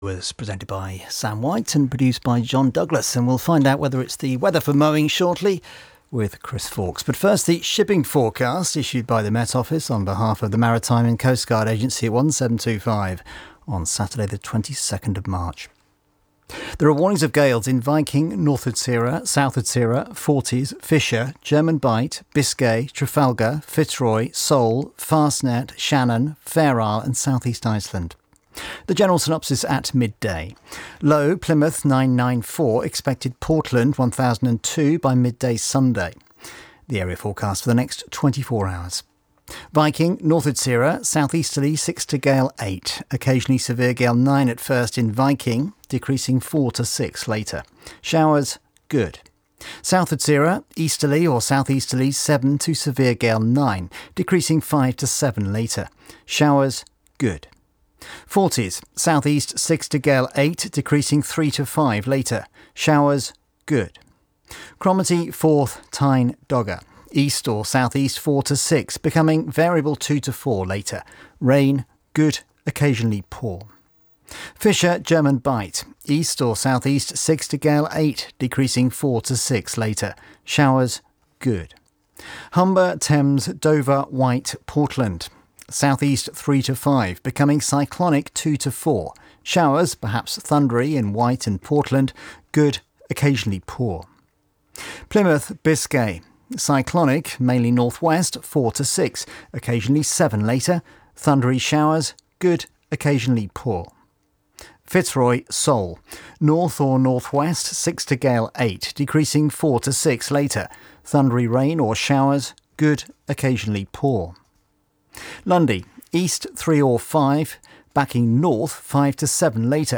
The latest weather reports and forecasts for UK shipping